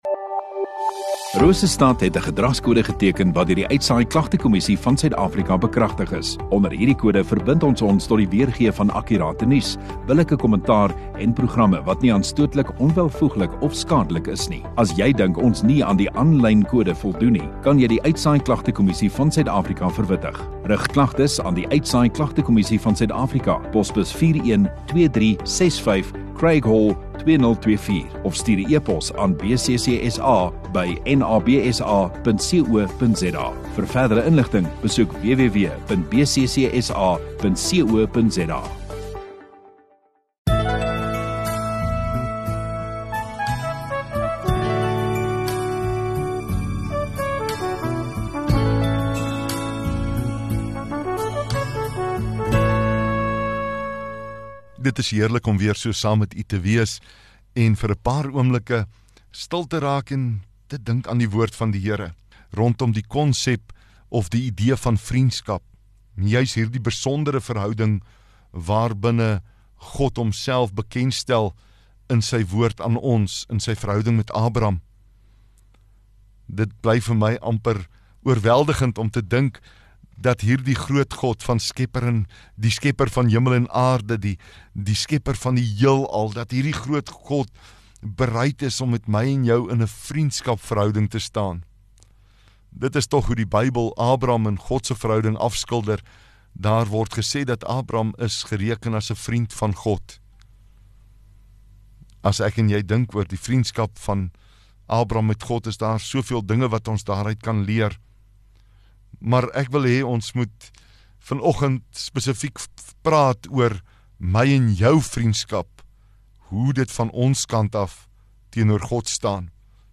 28 Aug Woensdag Oggenddiens